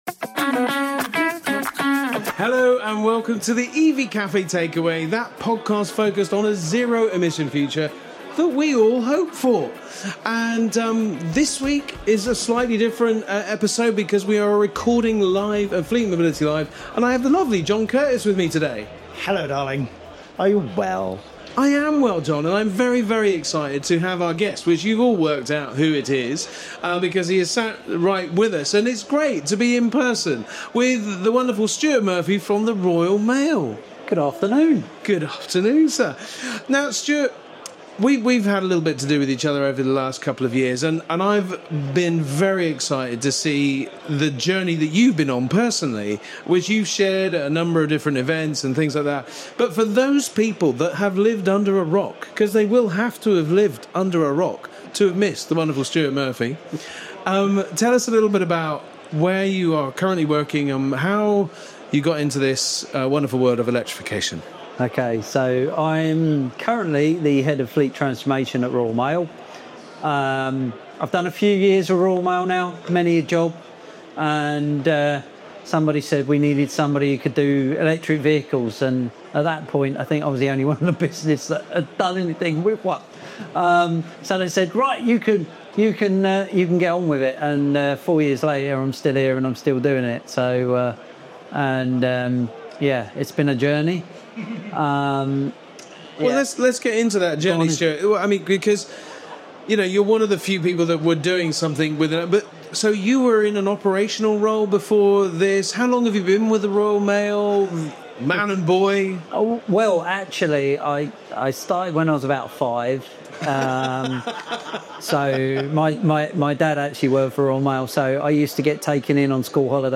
In this special live-recorded episode of The EV Café Takeaway